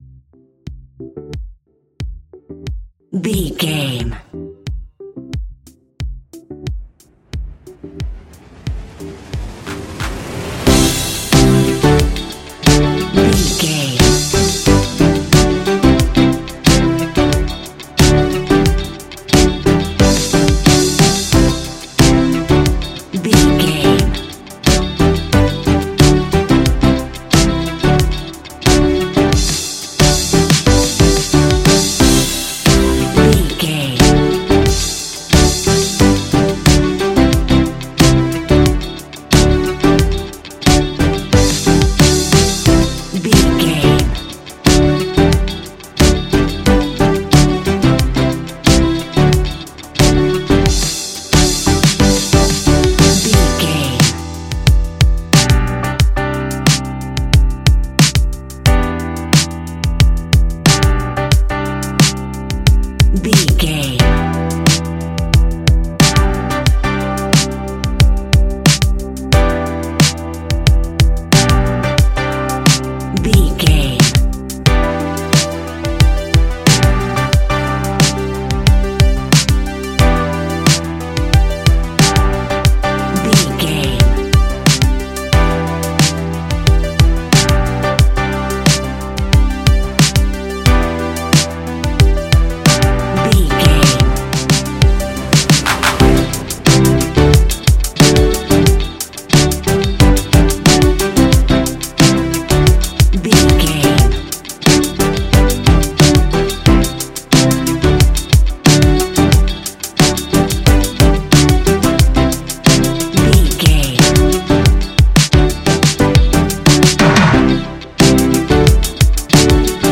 Ionian/Major
ambient
electronic
new age
chill out
downtempo
synth
pads
space music
drone